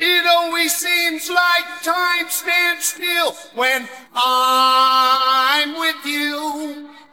vocals.wav